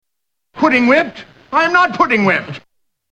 Tags: 3rd Rock from the Sun TV sitcom Dick Solomon John Lithgow Dick Solomon clips